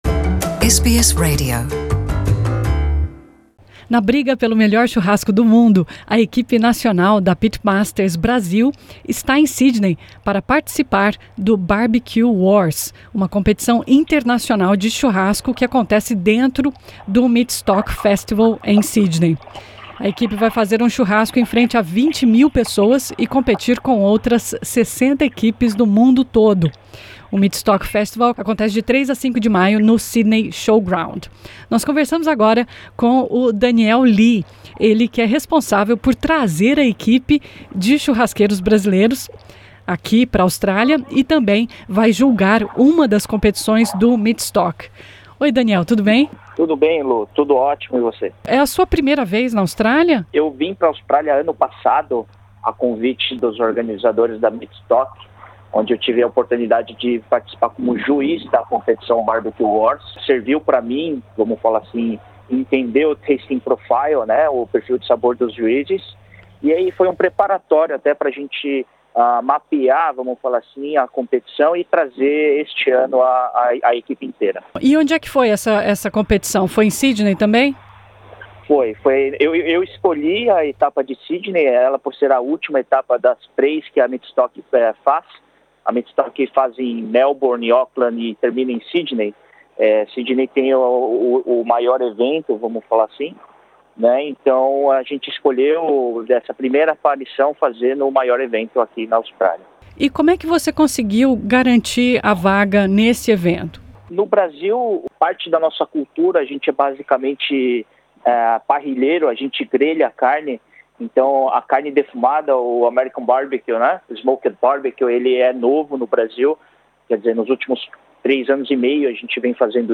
Na briga pelo melhor churrasco do mundo, a equipe nacional da Pitmasters Brasil está em Sydney para participar do Barbecue Wars, competição internacional de churrasco do Meatstock Festival. Ouça entrevista